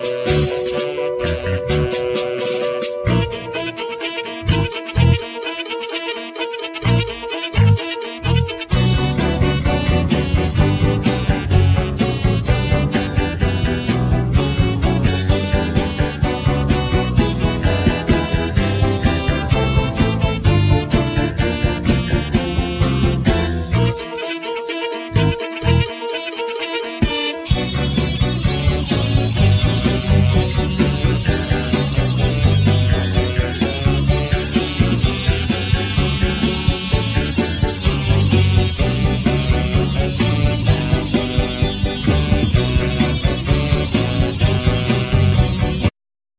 Guitar,Keyboards,Percussion,Timpani,Tublar bells
Bass,Vocal
Drums,Vocal